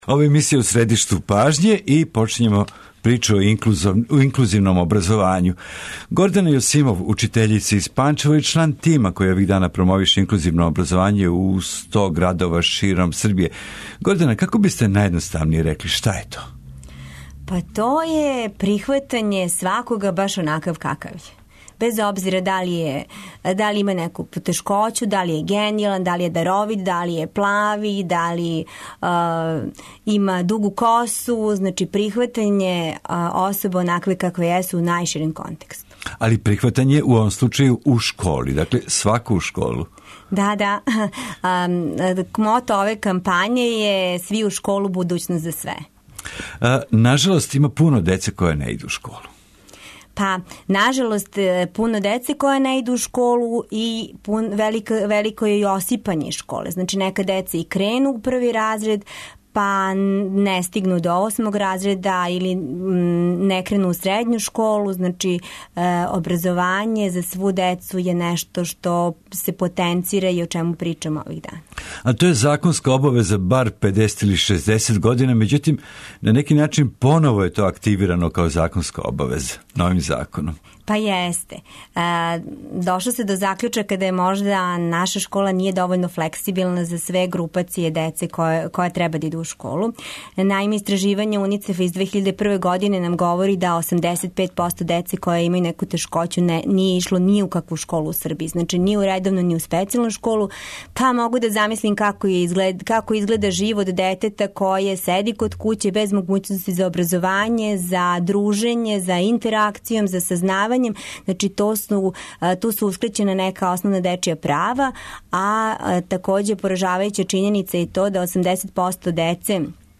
Чућемо такође искуства неколико учитеља који су прошли обуку за инклузивно образовање.